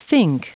Prononciation du mot fink en anglais (fichier audio)
Prononciation du mot : fink